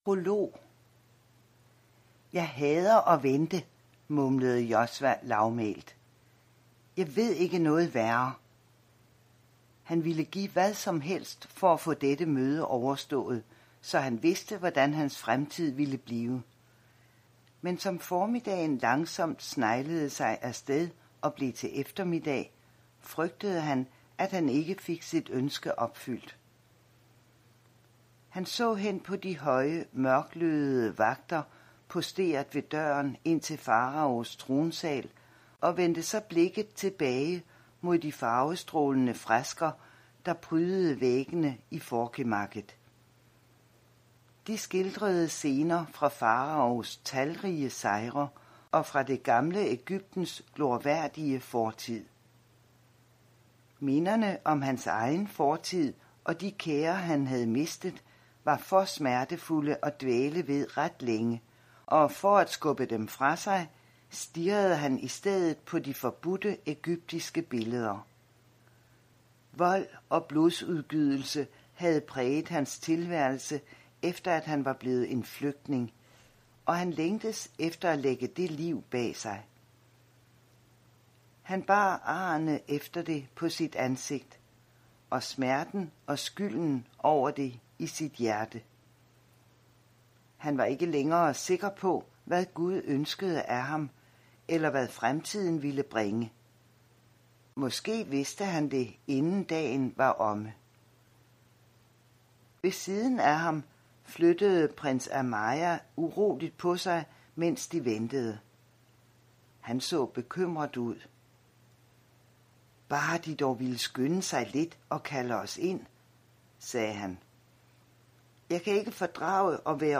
Hør et uddrag af Blandt guder Blandt guder Konge Krøniken V Format MP3 Forfatter Lynn Austin Bog Lydbog E-bog 99,95 kr.